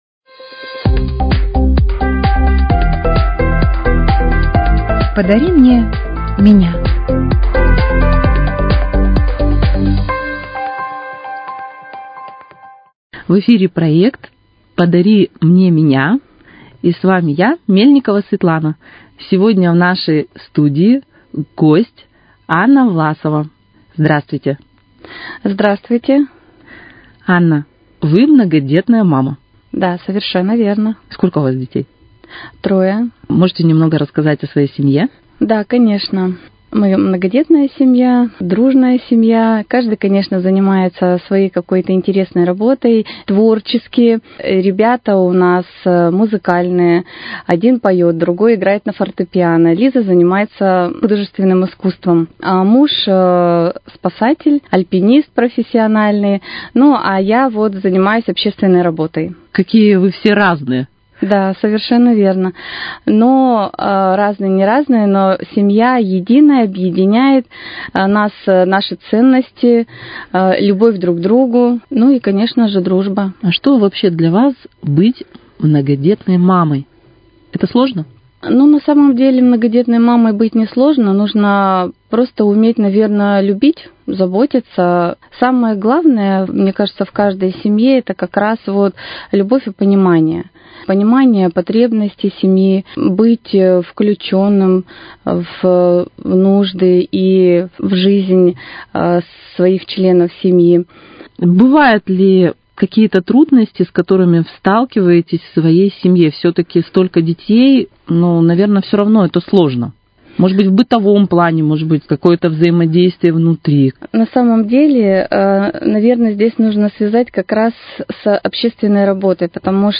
Вашему вниманию очередная передача из цикла "Подари мне меня".